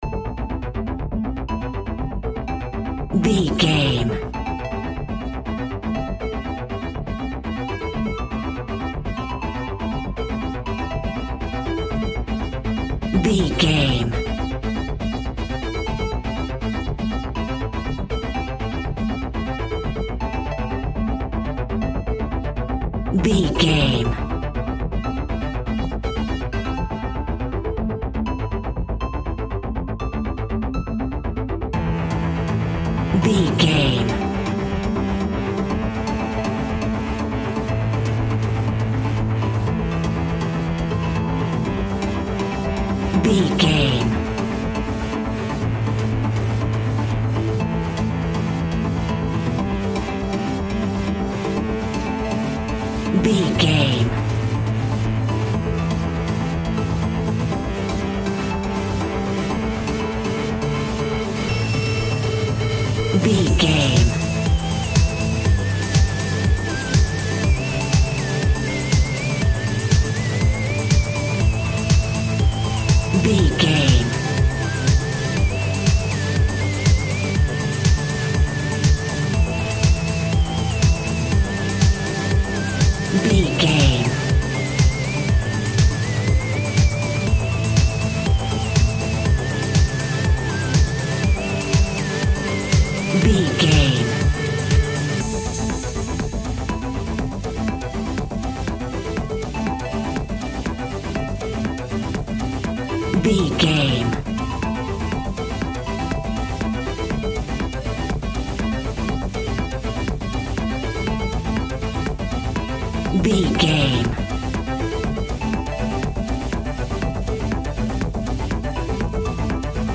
Pop Electronic Dance Music Full.
Fast paced
Aeolian/Minor
Fast
groovy
uplifting
driving
energetic
bouncy
synthesiser
drum machine
house
techno
trance
synth leads
synth bass
upbeat